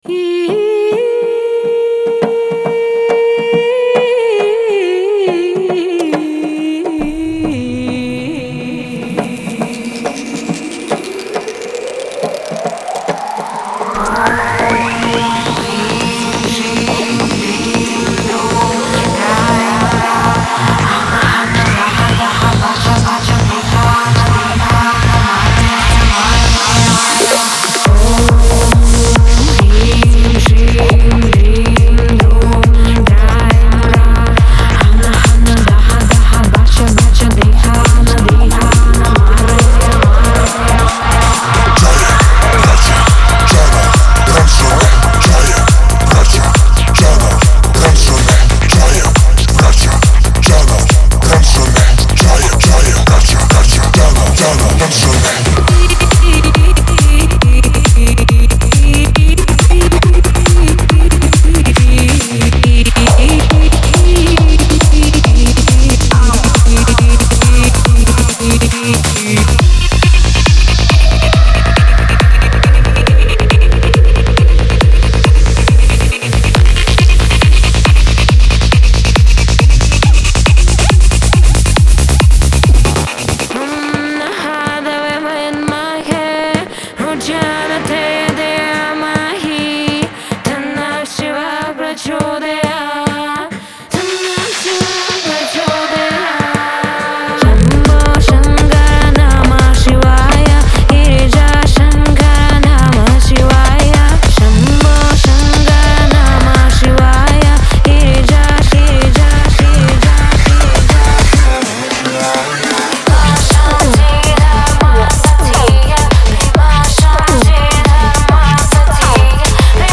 •138 BPM
•156咒语（56干燥，100处理）
•81种萨满音（37干，44湿）
•100 Adlib人声（39干，61湿）
•34个低音循环
•58个鼓循环
•42个合成器填充